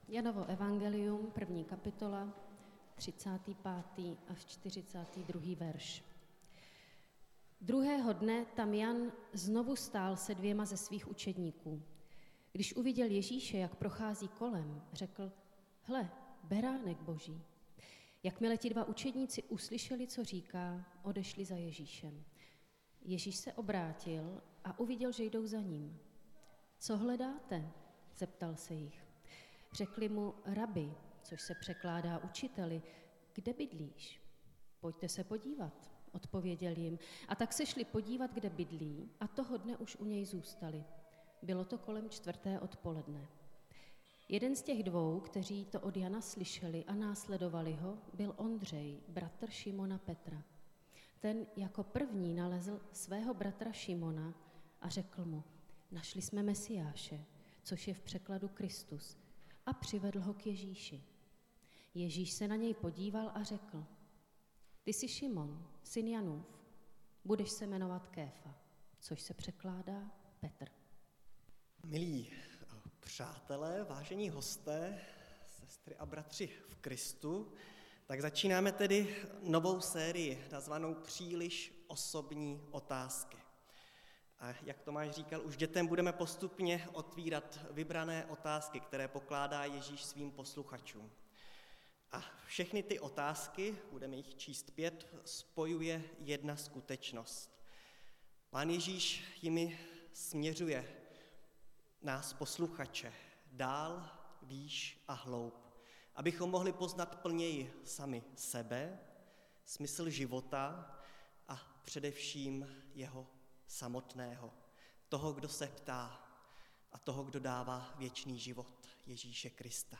Nedělení kázání – 29.1.2023 Co hledáte?